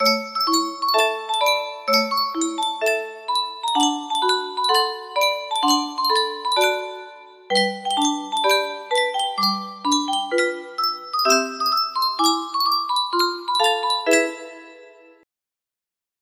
Yunsheng Music Box - Ballin' the Jack 1709 music box melody
Full range 60